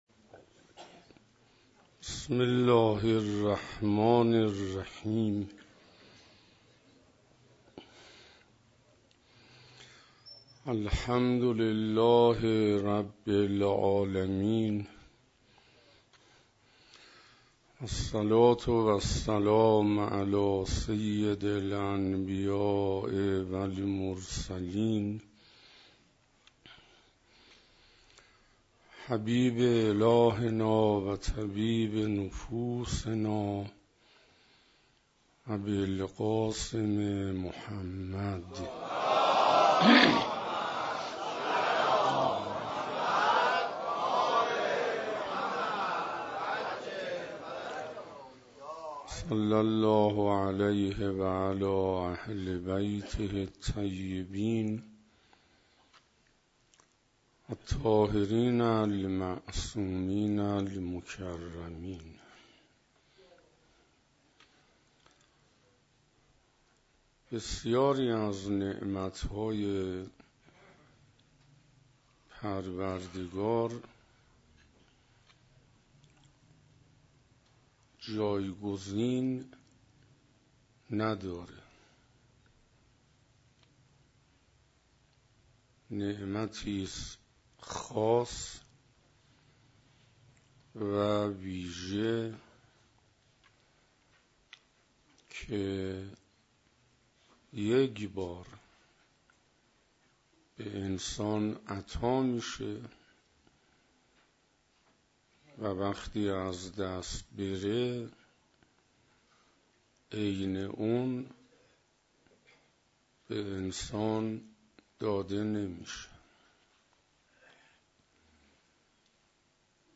فاطمیه 97 - حسینیه محبان الزهرا (س) - شب دوم - احسان و کار خیر